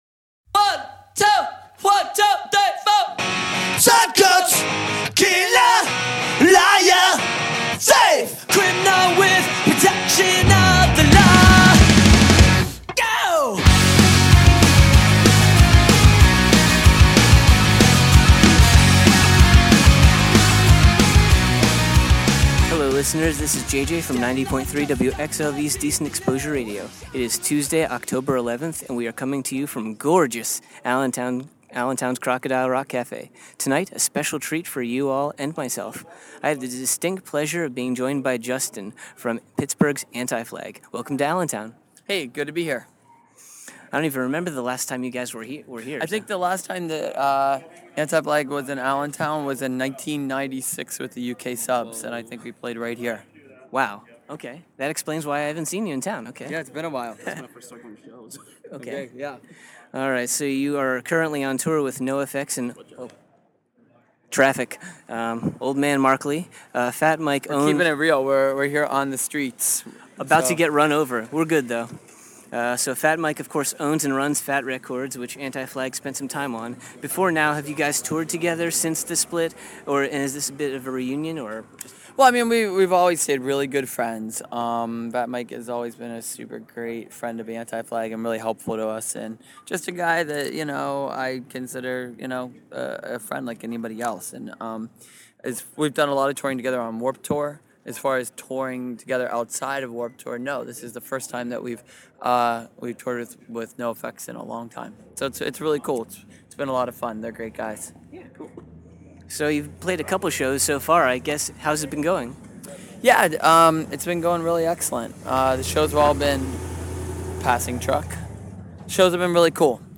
Exclusive: Anti-Flag Interview
12-interview-anti-flag.mp3